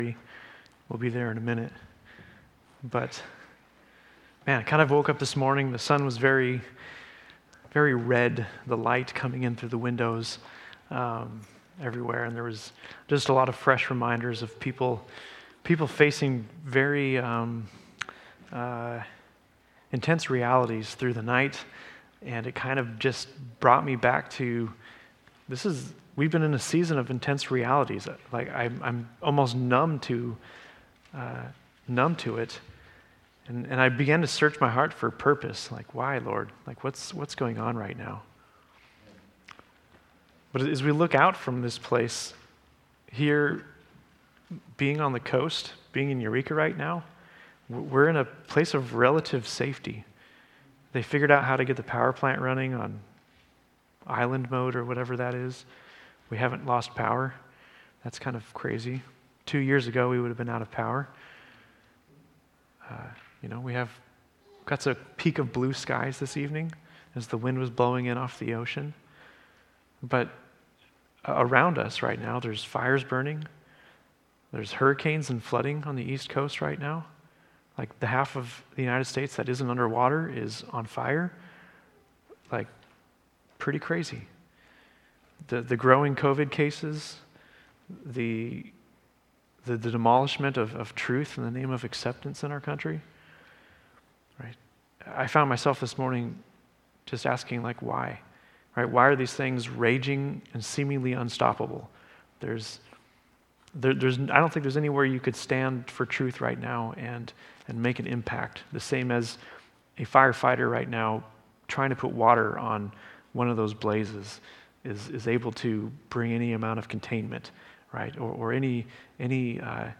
A message from the series "Ecclesiastes."